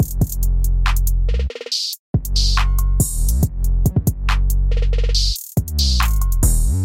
808是A小调。
Tag: 140 bpm Trap Loops Drum Loops 1.15 MB wav Key : Am FL Studio